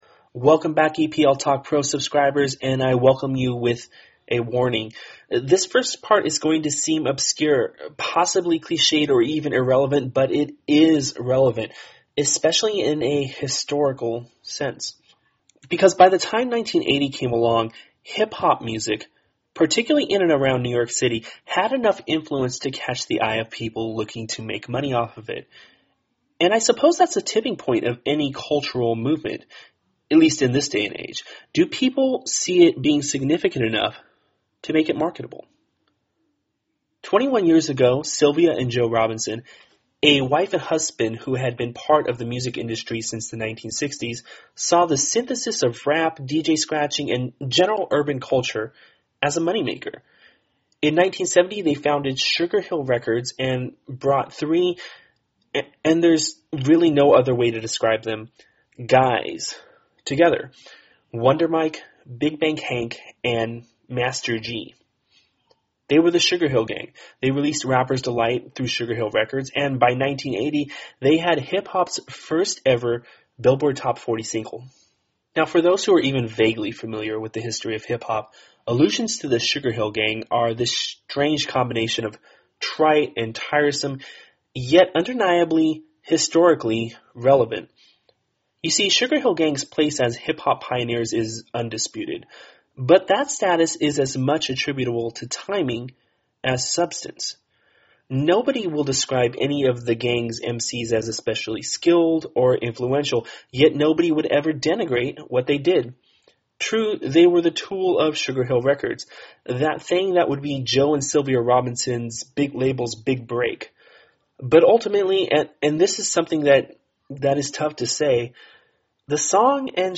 I was too until after I heard part 2 of Detailing The Pyramid, the audio documentary series.